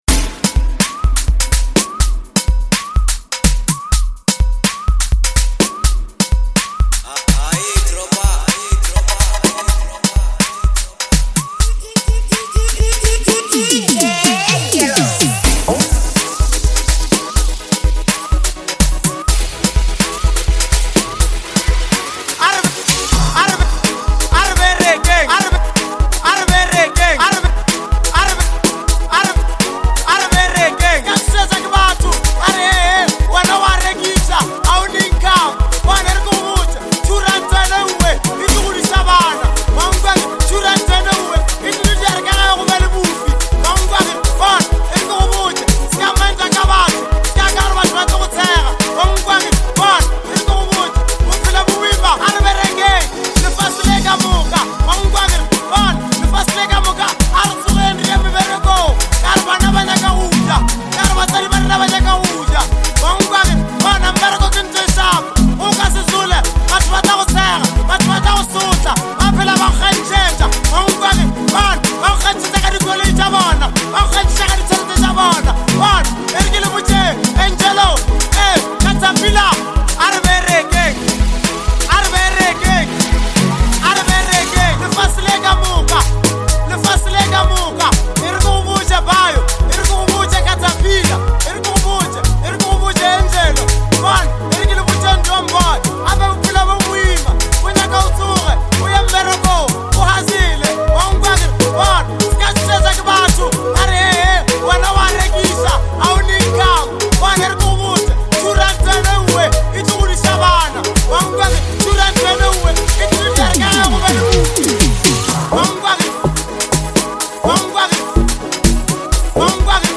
Where to Get More Street-Approved Amapiano